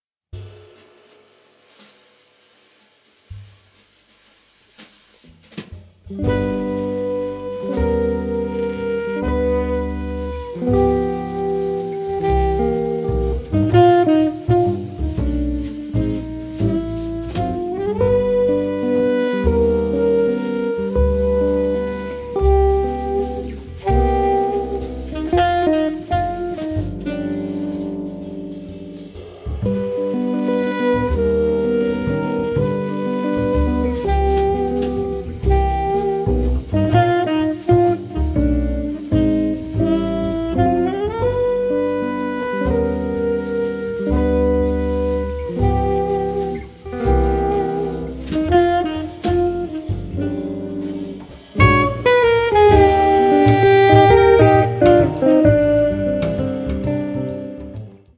guitar
saxophones
bass
drums